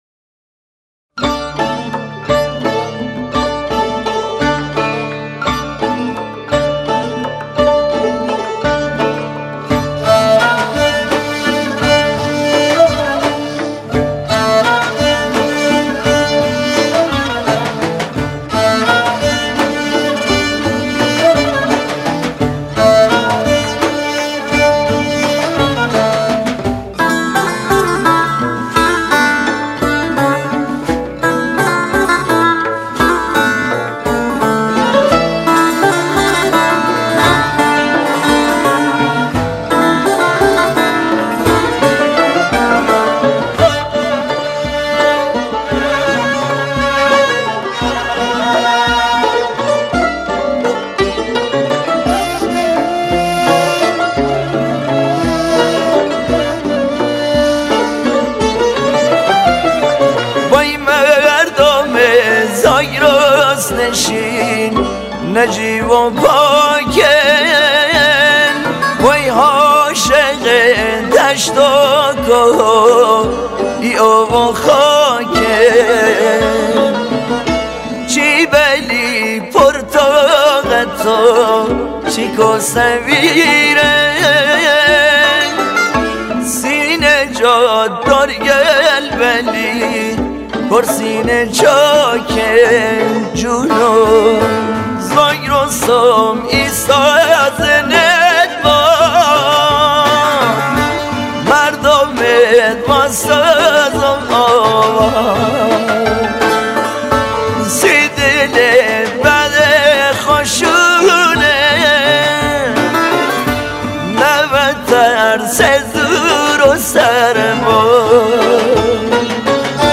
یک آهنگ لری شاد
آهنگ محلی لری تقدیم شما همراهان همیشگی ما